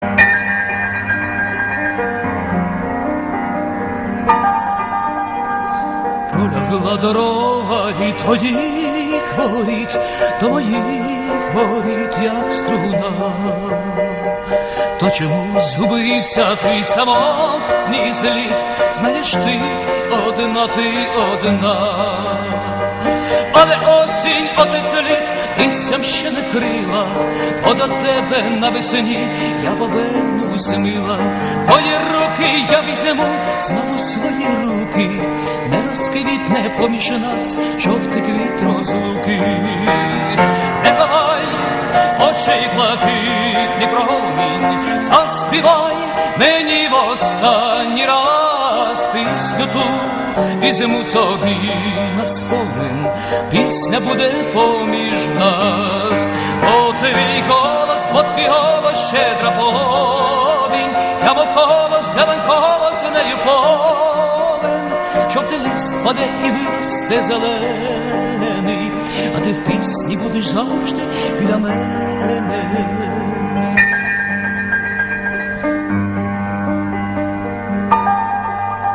• Качество: 256, Stereo
душевные
70-е
шлягер